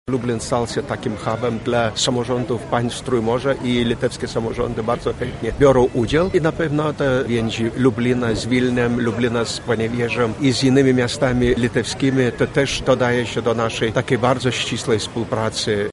O relacjach polsko-litewskich wypowiedział się Eduardas Borisovas, ambasador Litwy: